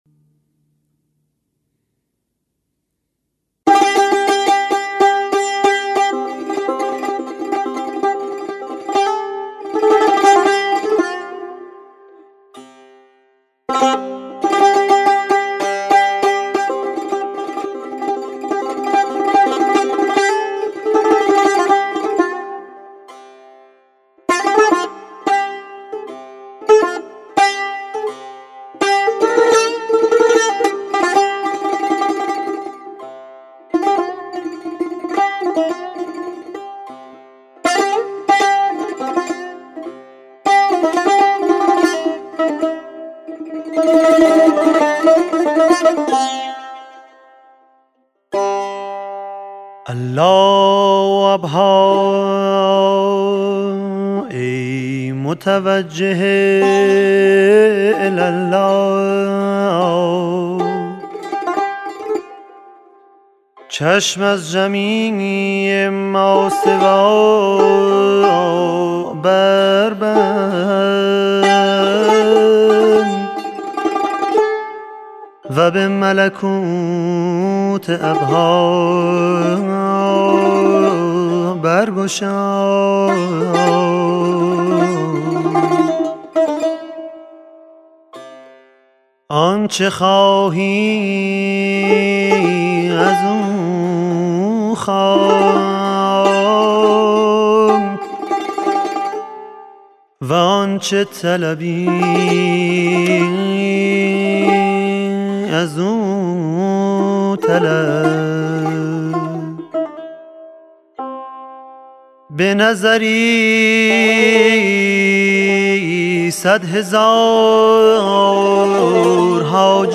قند پارسی لحن فارسی